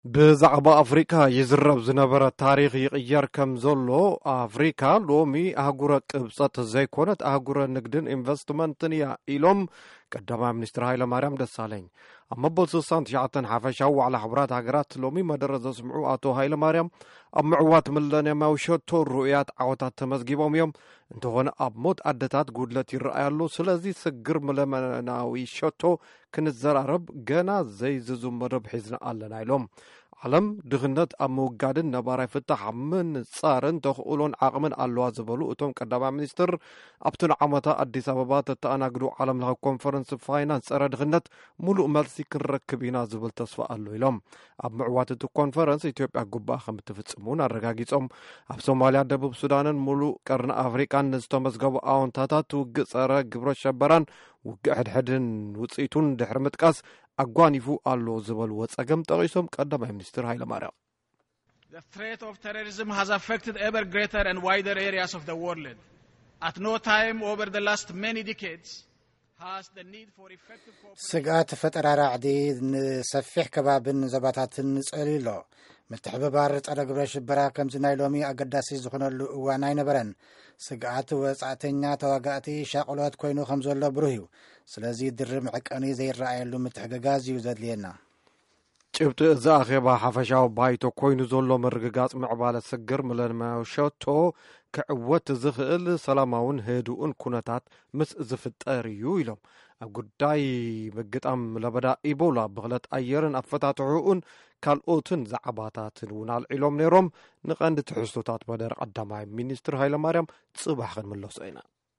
ካብ መደረ ቀ/ሚ ሃይለማርያም ደሳለኝ